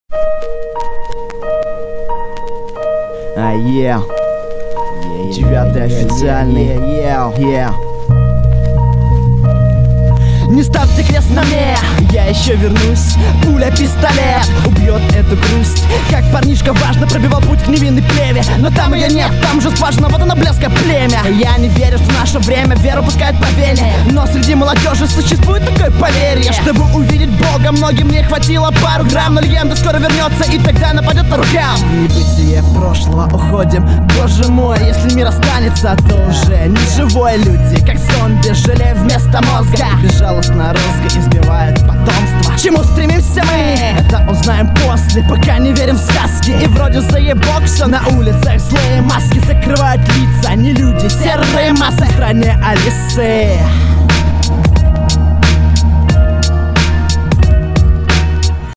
довольно сыро звучит видно что нет особо опыта...могу ошибаться канечно же